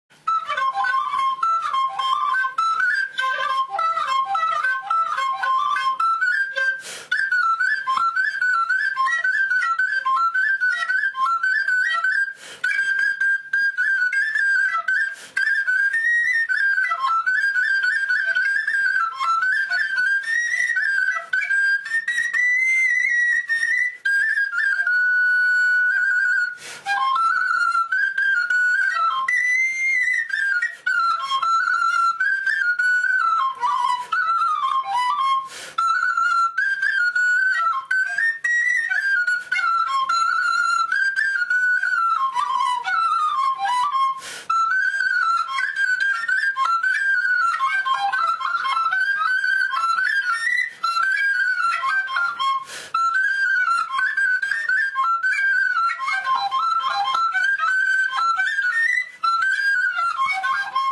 Tondokument: Kleine Hirtenfl�te (Vrancea)
kleinen Hirtenfl�te (fluere)
Romanesti / Vrancea / Rum�nien Wenn auf dieser Seite kein Ton erklingt